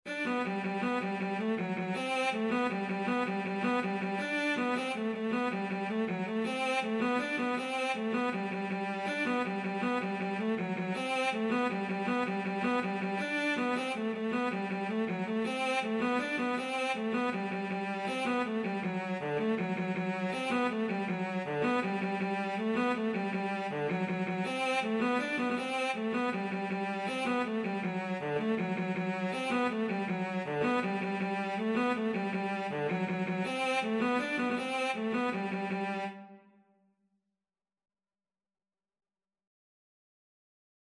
Cello version
6/8 (View more 6/8 Music)
G major (Sounding Pitch) (View more G major Music for Cello )
Cello  (View more Easy Cello Music)
Traditional (View more Traditional Cello Music)